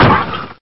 damage100_2.ogg